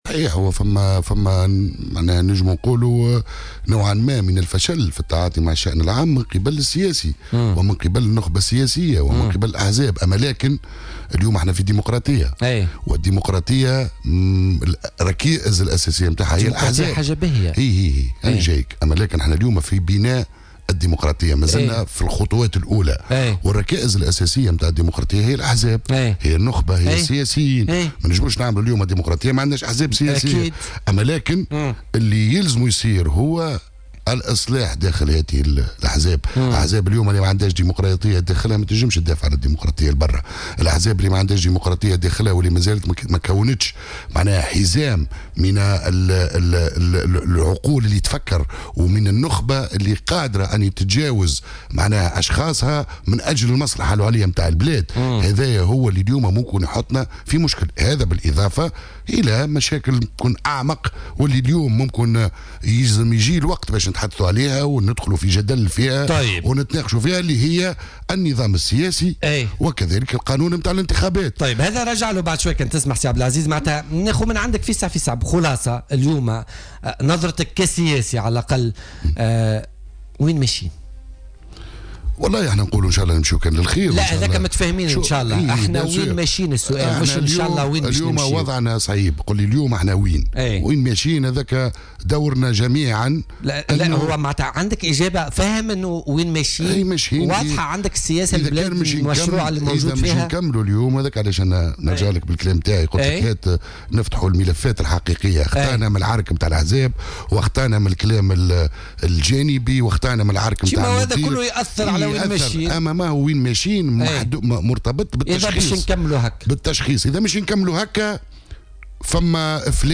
وتساءل القطي، ضيف بوليتيكا اليوم الأربعاء، عن نجاعة نظام الحكم القائم والذي اعتبر أنه لا يخول للسلطة الحكم والتعامل مع مختلف التحديات على غرار المطلبية الاجتماعية والإصلاحات الكبرى، والمفات الاقتصادية والسياسية في ظل التجاذبات الموجودة بين مختلف مكونات المشهد السياسي والحزبي في تونس.